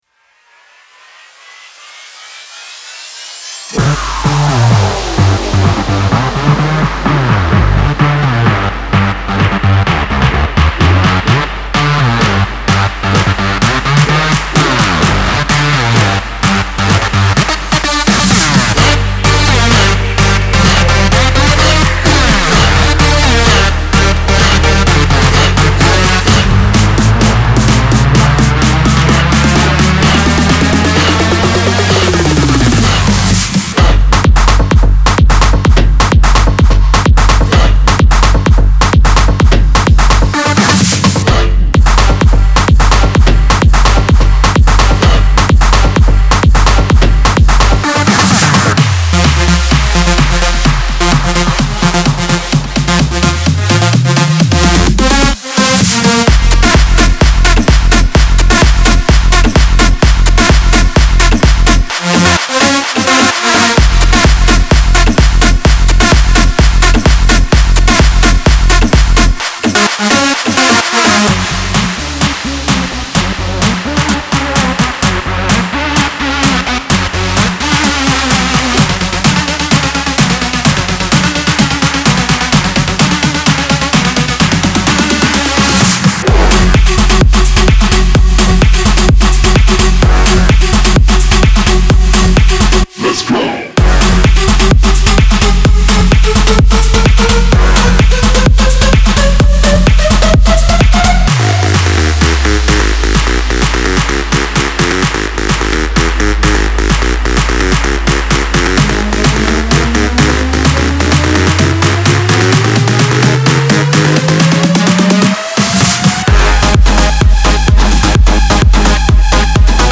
• 20 Vocal Chunks